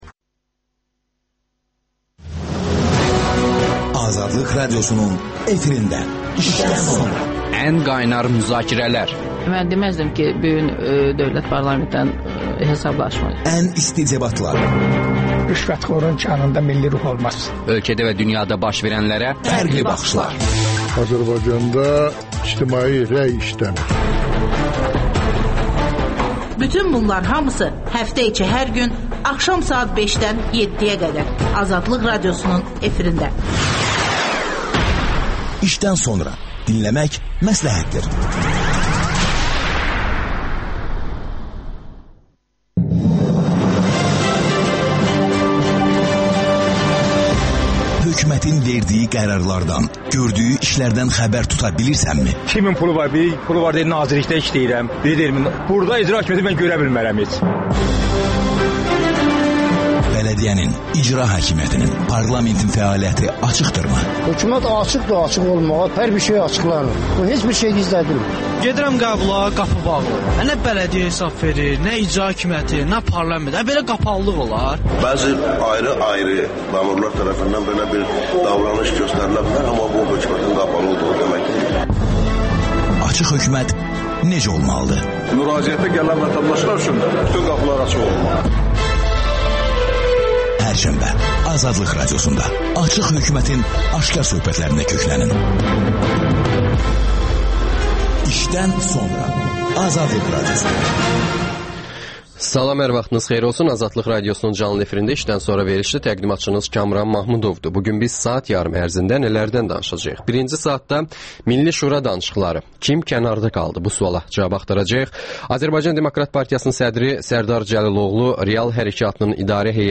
canlı efirdə fikir mübadiləsi aparırlar.